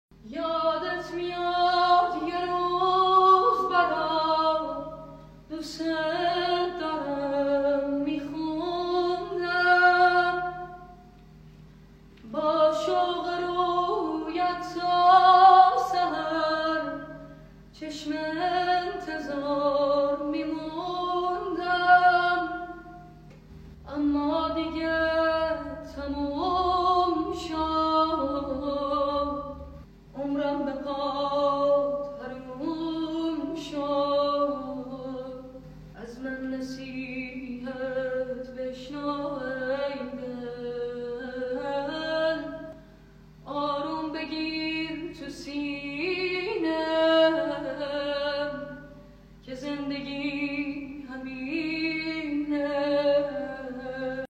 صدای پسر